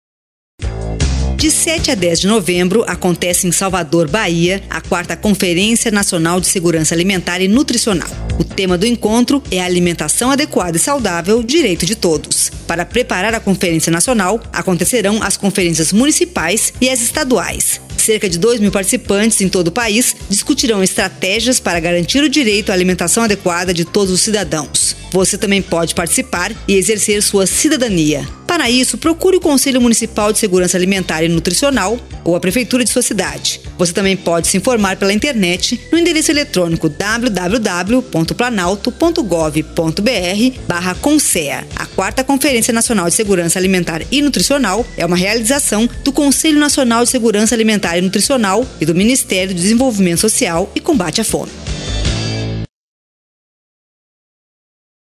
Spot de rádio da 4ª Conferência Nacional de Segurança Alimentar e Nutricional